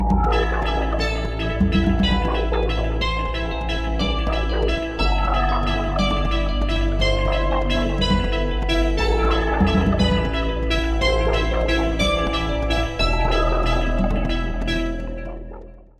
peur - froid - suspense - angoissant - sombre